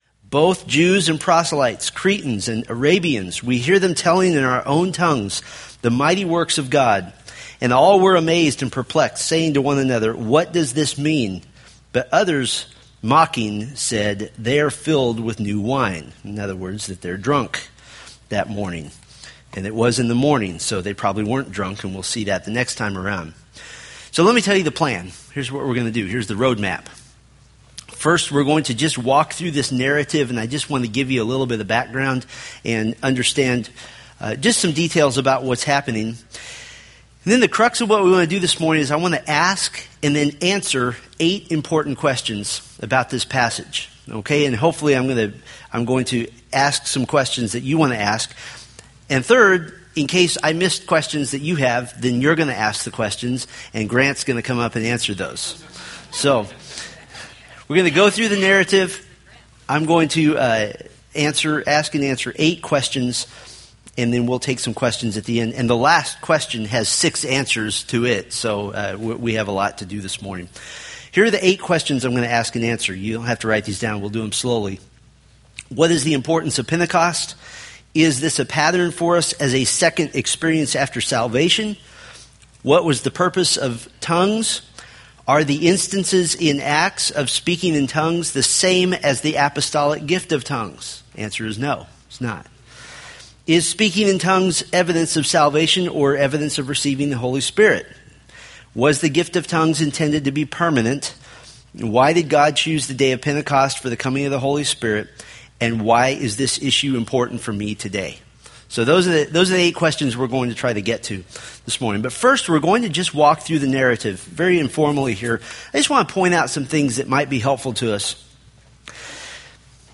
Date: Nov 17, 2013 Series: Acts Grouping: Sunday School (Adult) More: Download MP3